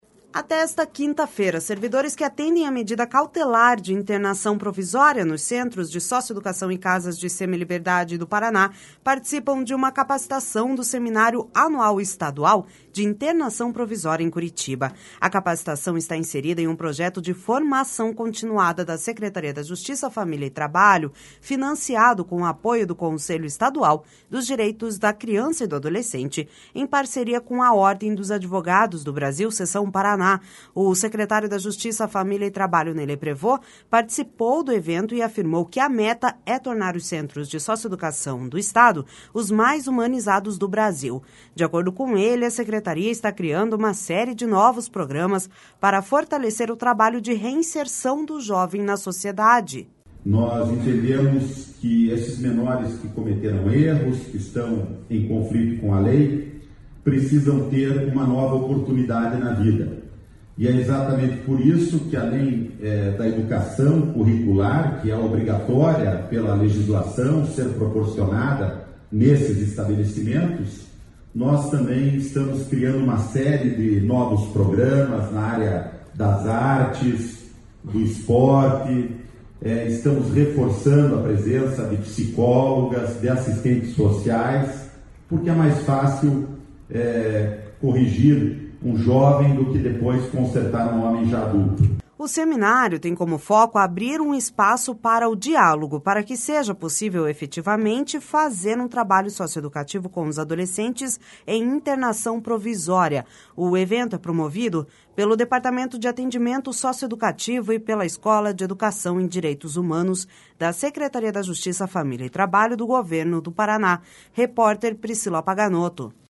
O secretário da Justiça, Família e Trabalho, Ney Leprevost, participou do evento e afirmou que a meta é tornar os centros de socioeducação do Paraná os mais humanizados do Brasil. De acordo com ele, a secretaria está criando uma série de novos programas para fortalecer o trabalho de reinserção do jovem na sociedade.// SONORA NEY LEPREVOST//O seminário tem como foco abrir um espaço para o diálogo para que seja possível, efetivamente, fazer um trabalho socioeducativo com os adolescentes em internação provisória.